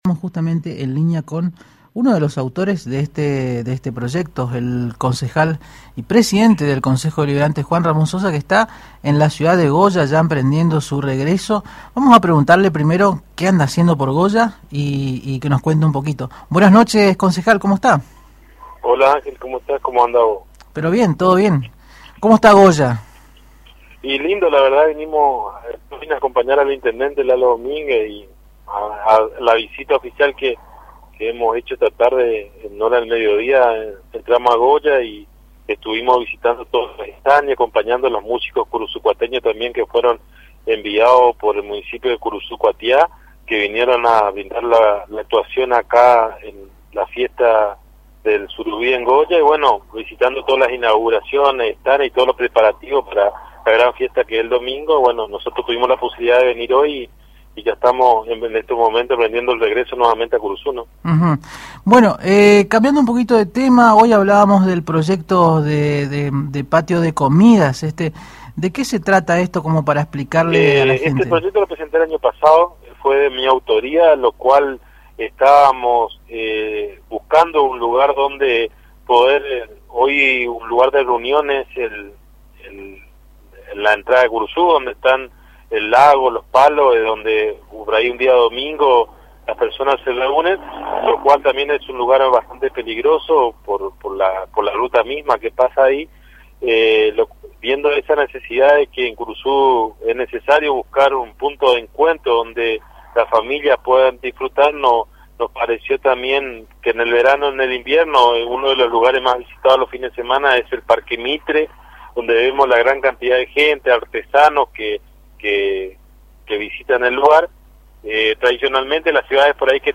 (Audio) Lo señaló el presidente del Concejo Deliberante de la ciudad de Curuzú Cuatiá, el concejal Juan Ramón Sosa, quien momentos antes de venir de Goya dialogó con Agenda 970 a través de la Am 970 Radio Guarani señalando que no será necesario tirar abajo ningún árbol para el proyecto de su autoría, denominado "Patio de Comidas", que fue aprobado por unanimidad en la última sesión del HCD.
Desde la ciudad de Goya, en virtud de la Fiesta del Surubí, y acompañando al gabinete municipal de Curuzú Cuatiá, el concejal Juan Ramón Sosa, minutos antes de emprender su regreso a Curuzú, se refirió al proyecto de su autoría, presentado el año pasado y aprobado por unanimidad de los tres bloques legislativos del parlamento comunal.